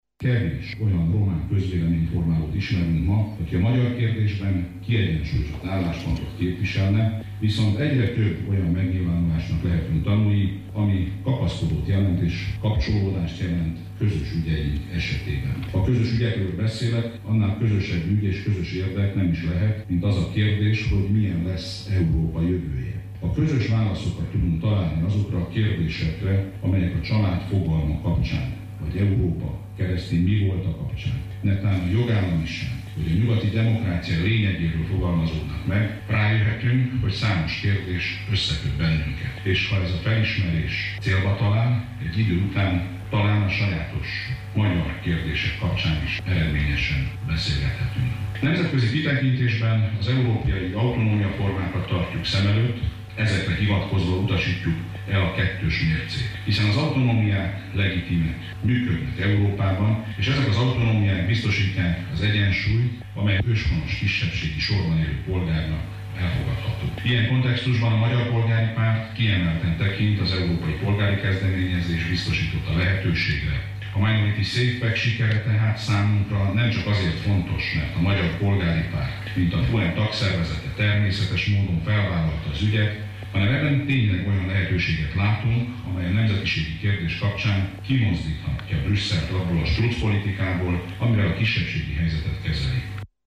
A Magyar Polgári Párt országos önkormányzati ülését tartják ma Marosvásárhelyen.